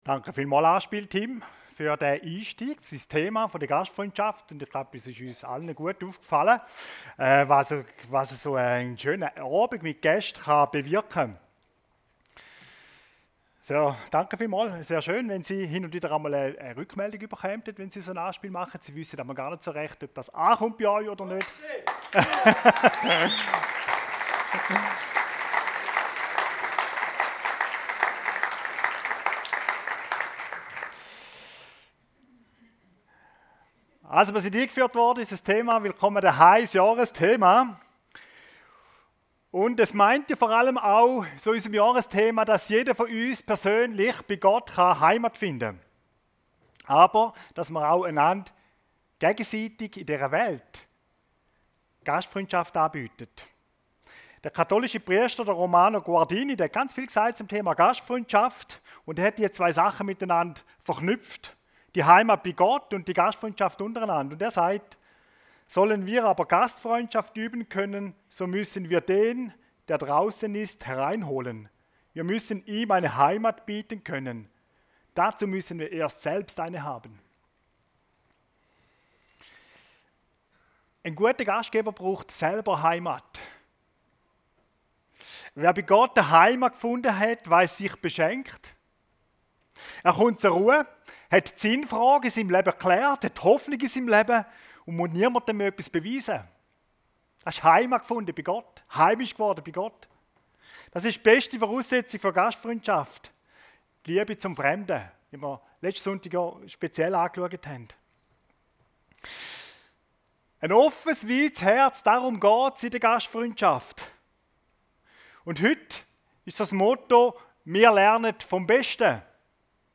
Vom besten Gastgeber lernen – seetal chile Predigten